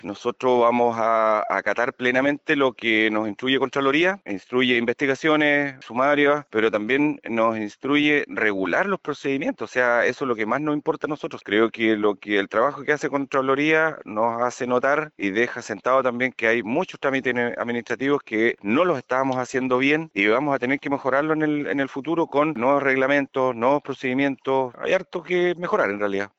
El alcalde de Lago Ranco, Miguel Meza, en conversación con Radio Bío Bío confirmó el inicio de un sumario para determinar eventuales responsabilidades.